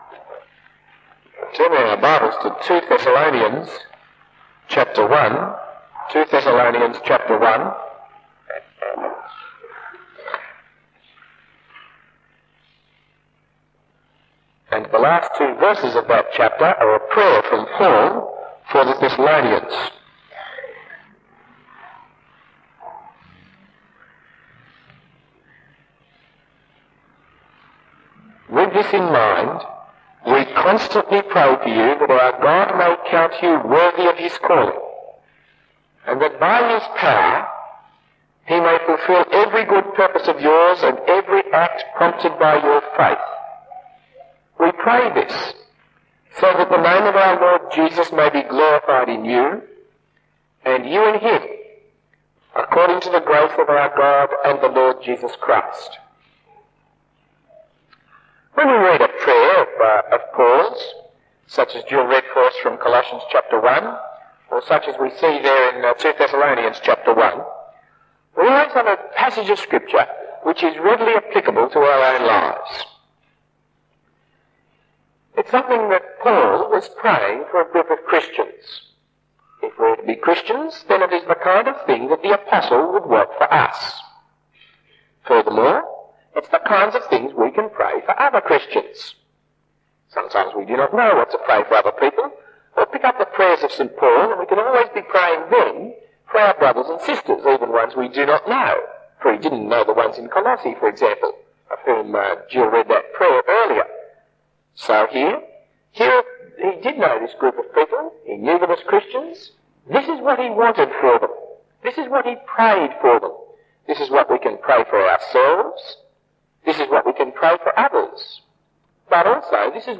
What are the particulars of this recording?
Talk 9 of 11 in the series Thessalonians 1979 given at St Matthias. Apologies that the quality of this recording is not very good.